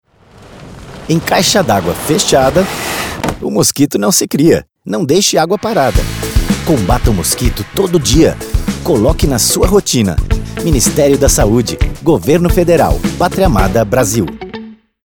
Spot - Mosquito Caixa D'água